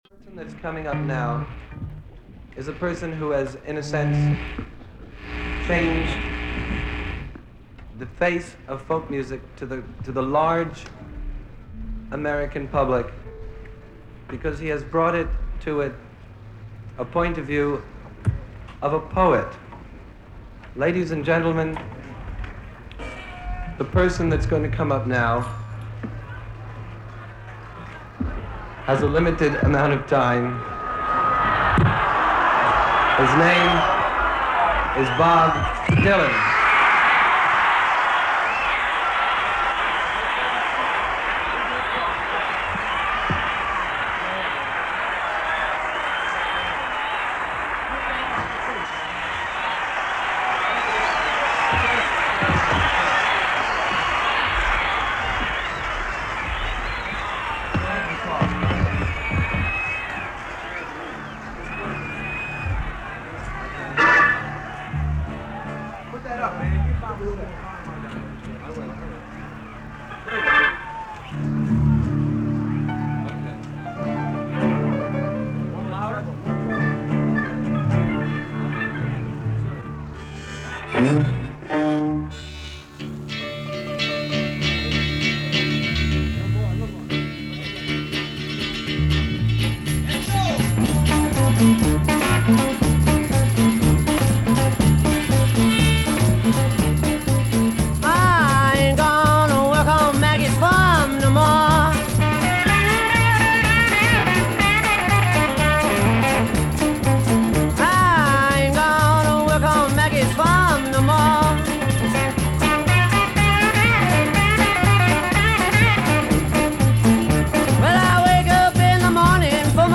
Tuning up. Audience reaction.